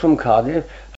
the-phonology-of-rhondda-valleys-english.pdf
5_4_4.2._from_Cardiff.mp3